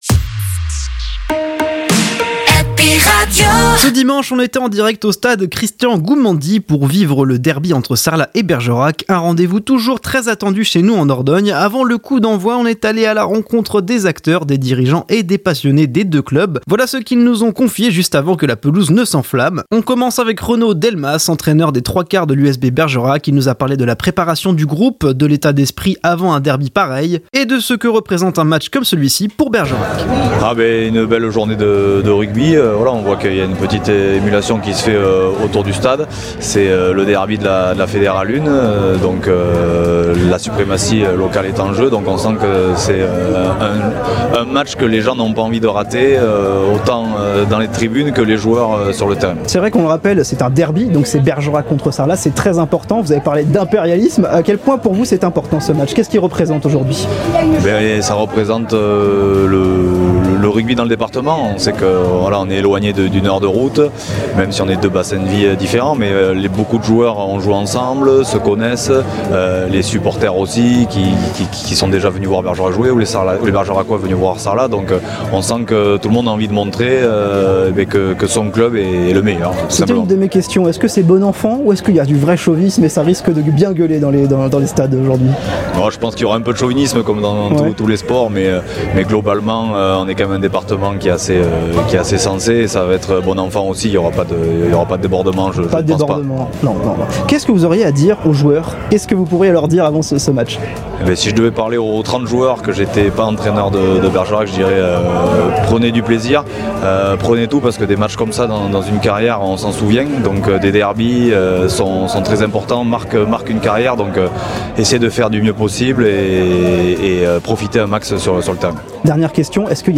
Les interviews Happy Radio Derby Sarlat – Bergerac : les réactions du match !